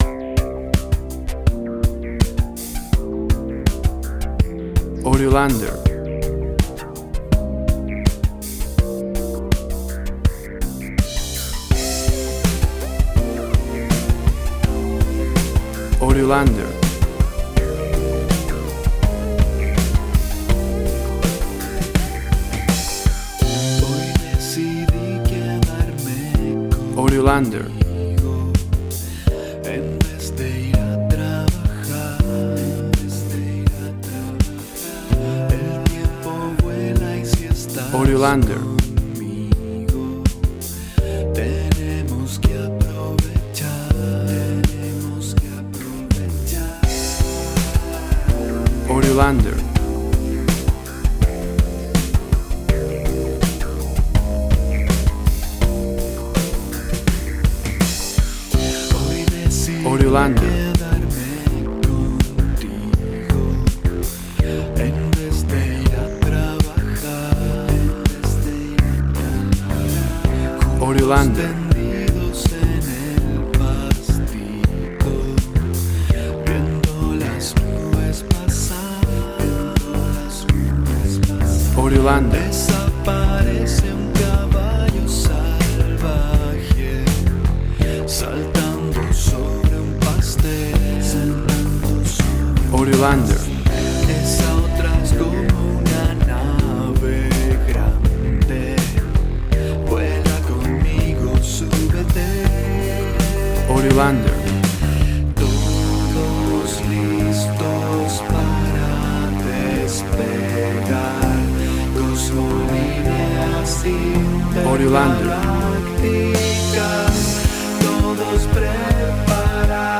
Lovely song for children, inspiring and tender.
Tempo (BPM): 85